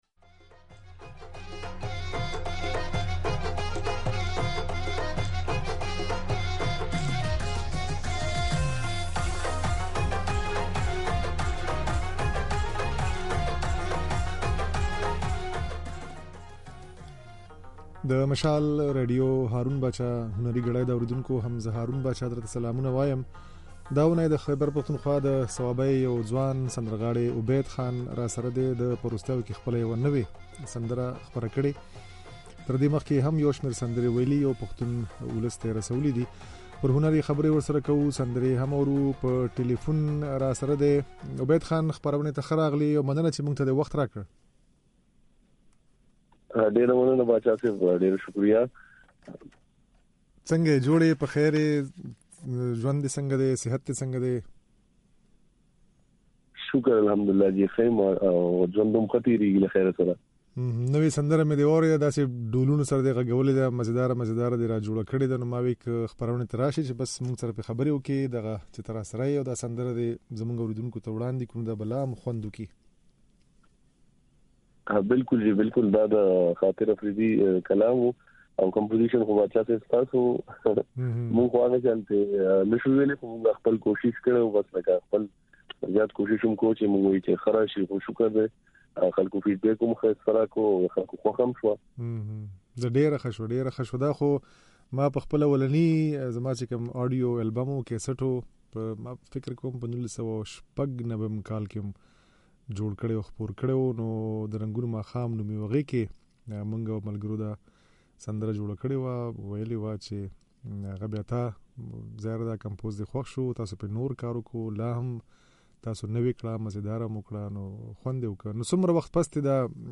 ځينې سندرې يې د غږ په ځای کې اورېدای شئ.